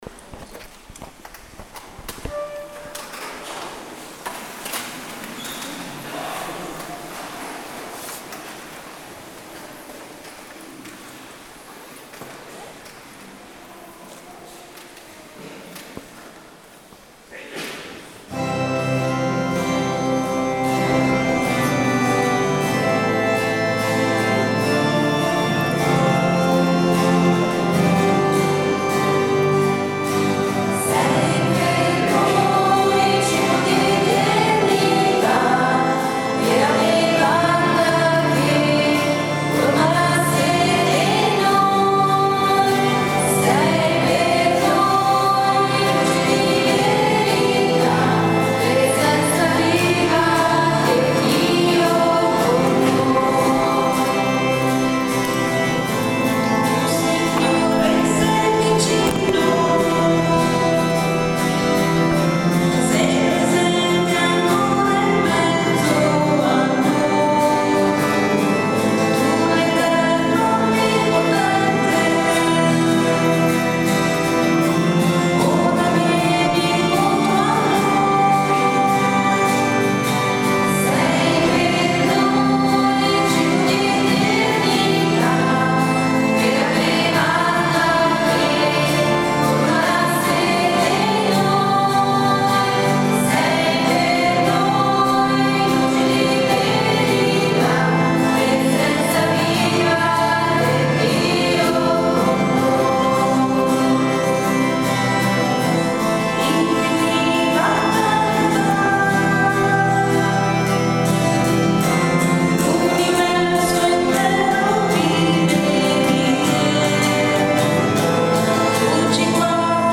Eucarestia
Sia allora che oggi, ho registrato il Canto proposto durante l’eucarestia;